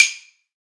soft-hitwhistle.ogg